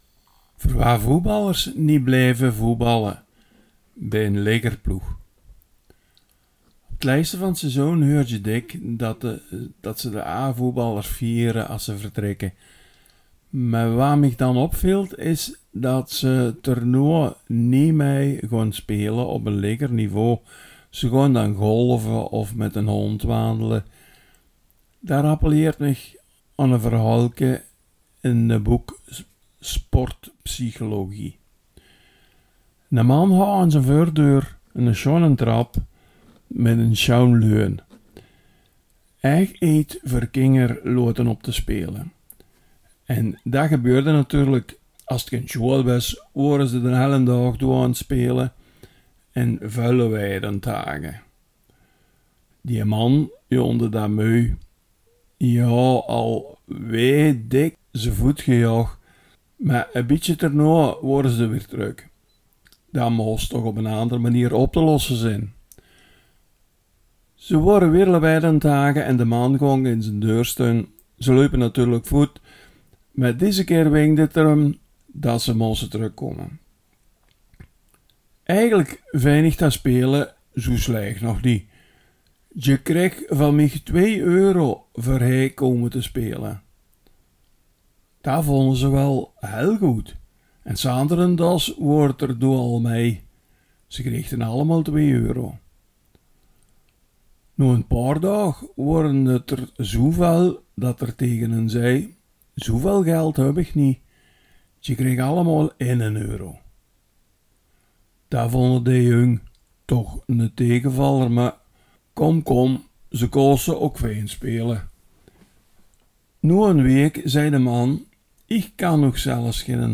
Veldeke Belgisch Limburg | Belgisch-Limburgse dialecten